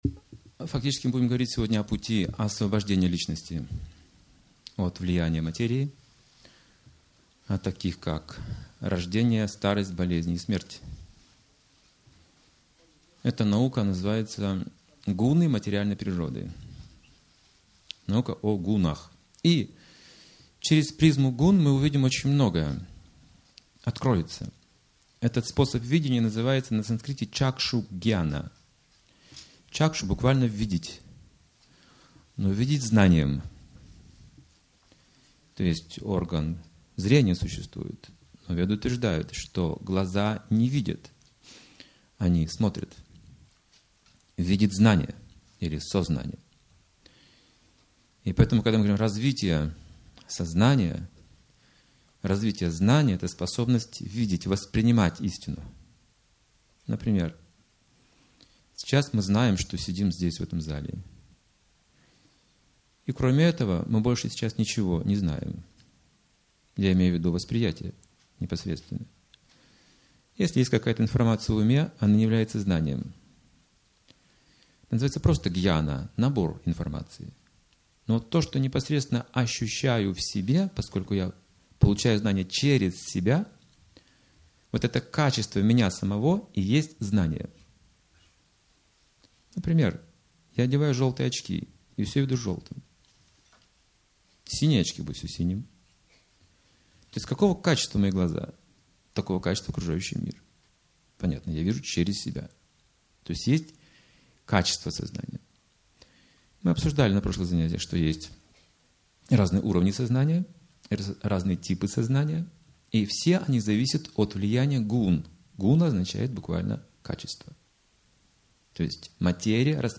Лекция, раскрывающая истинный путь освобождения: о том, как преодолеть влияния гун материальной природы и победить старость, болезнь и смерть.